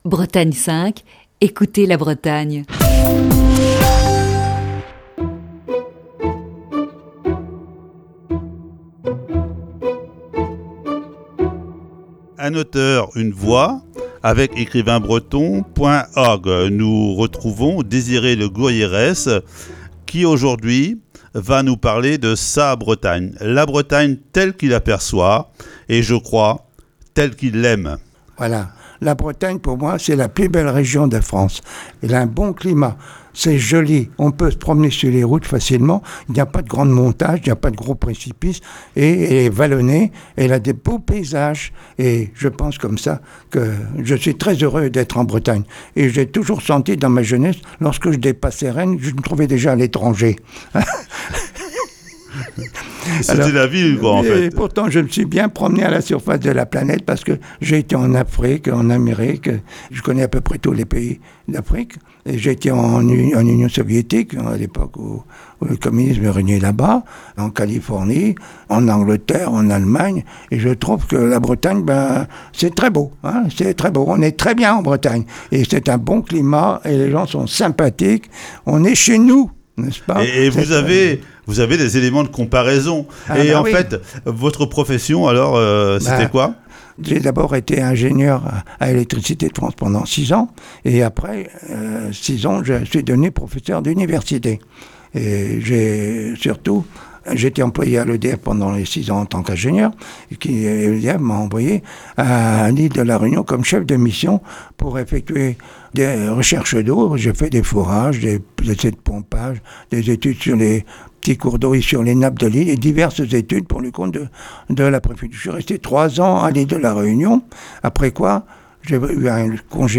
Voici ce mardi, la deuxième partie de cette série d'entretiens.